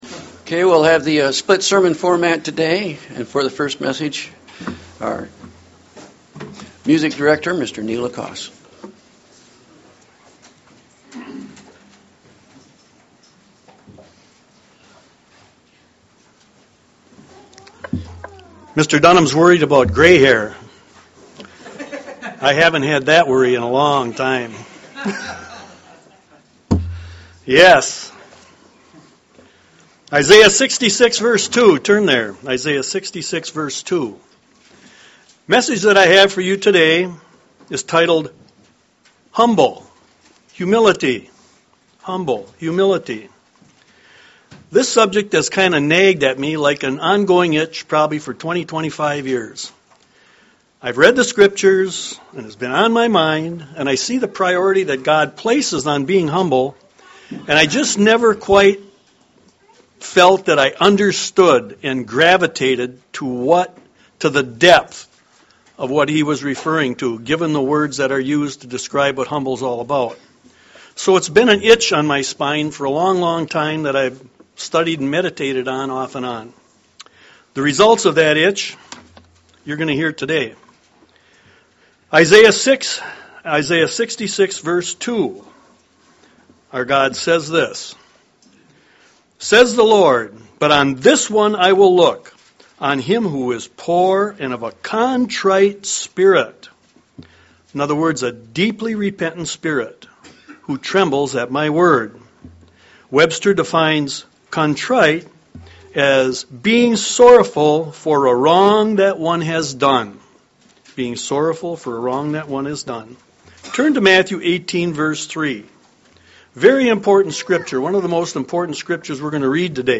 Sermons
Given in Lansing, MI